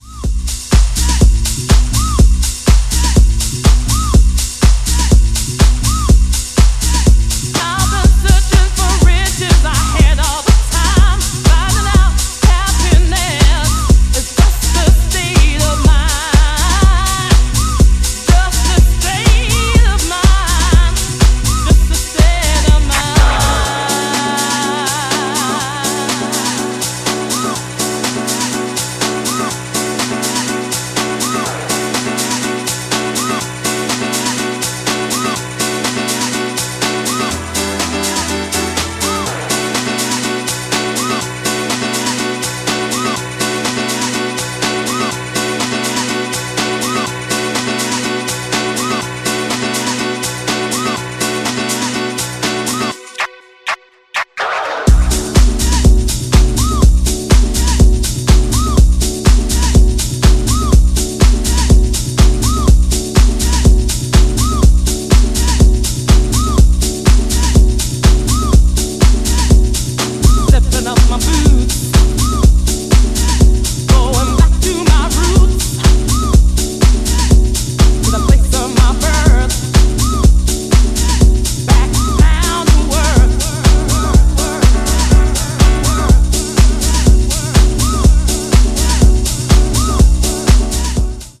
ジャンル(スタイル) HOUSE